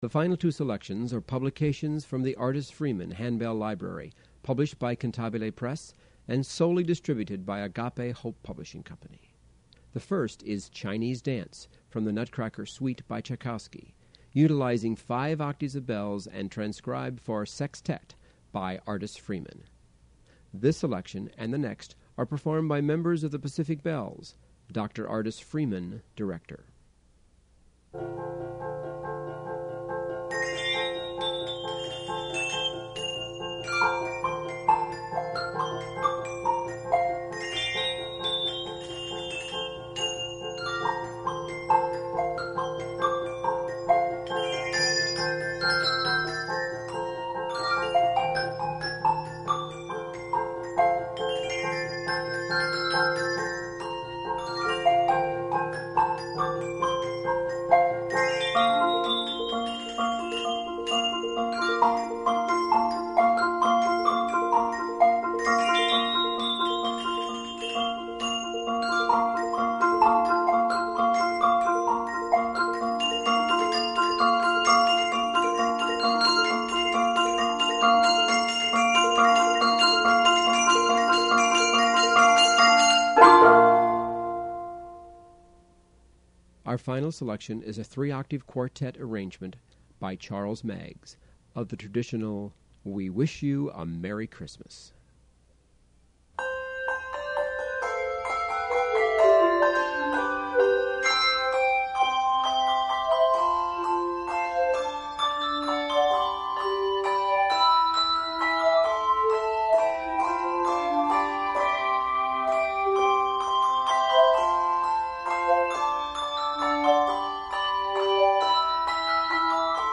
Handbell Quartet